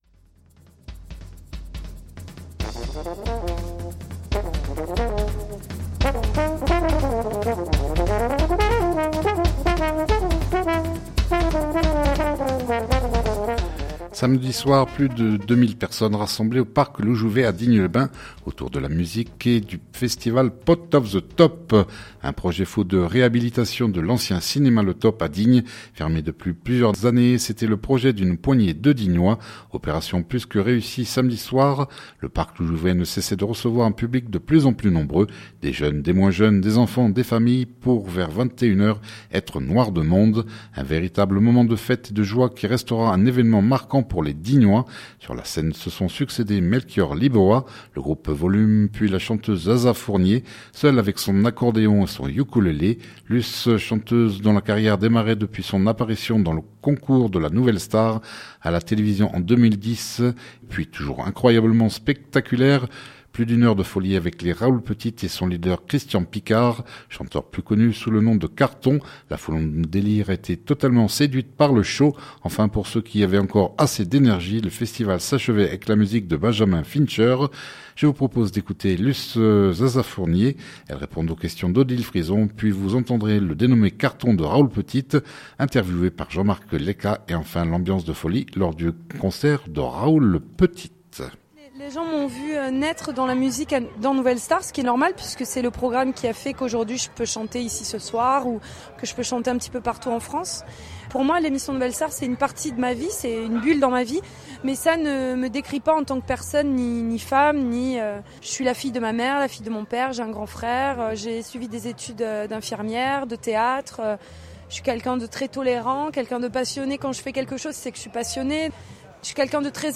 l’ambiance de folie lors du concert de Raoul Petite